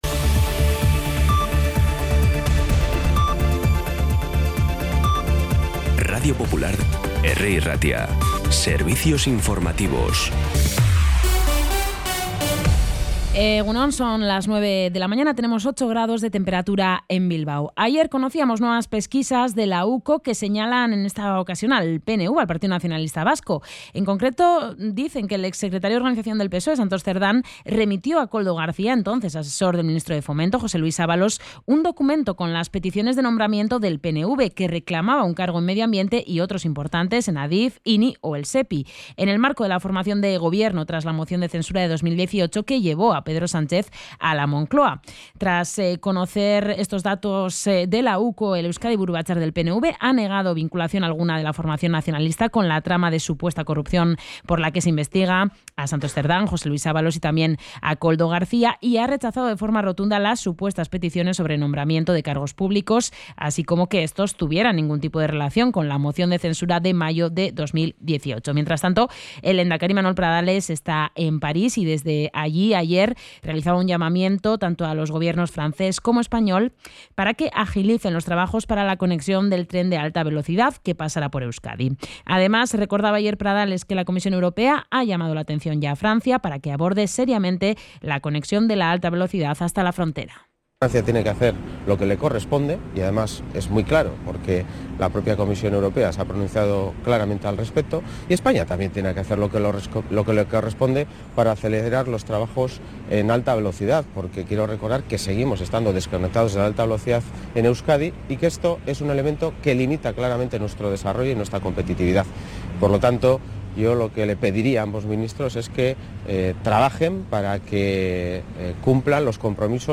Las noticias de Bilbao y Bizkaia de las 9 , hoy 19 de noviembre
Los titulares actualizados con las voces del día.